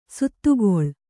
♪ suttugoḷ